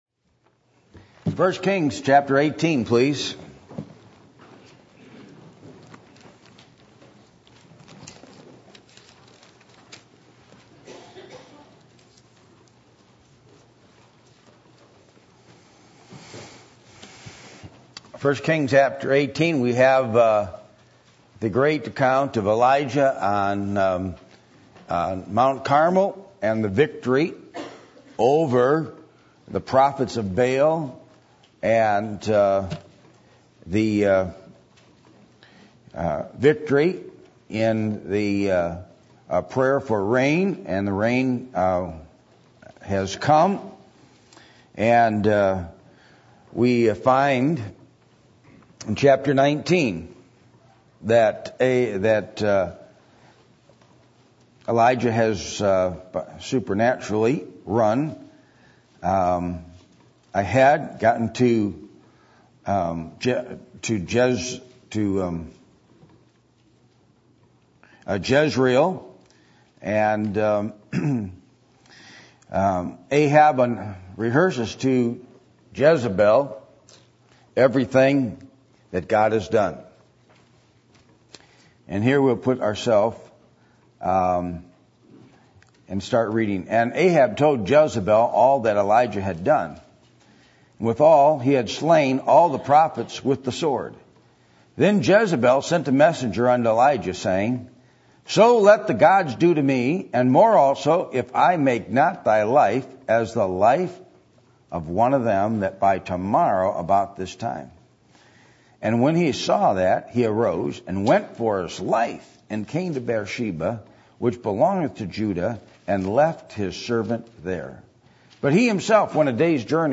Passage: 1 Kings 19:1-18 Service Type: Sunday Evening